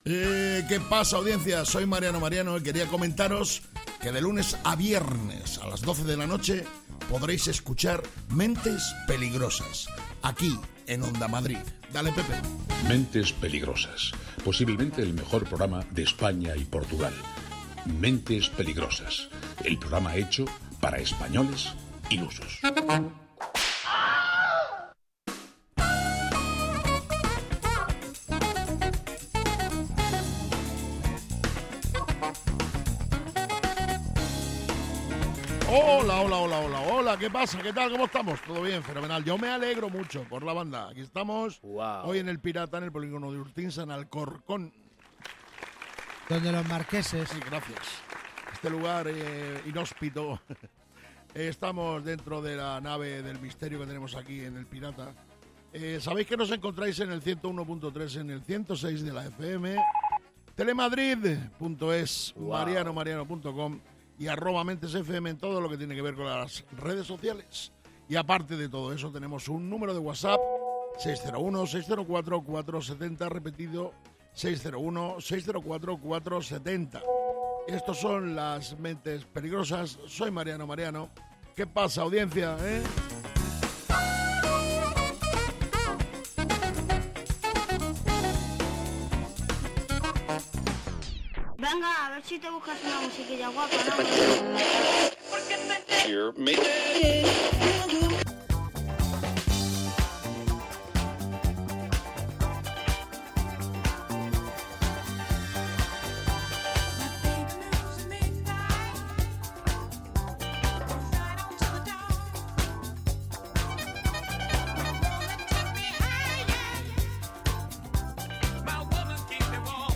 Mentes Peligrosas es humor, y quizás os preguntaréis, ¿y de qué tipo de humor es?, pues del que te ríes, porque si no, podría ser una bicicleta, un destornillador, cualquier cosa.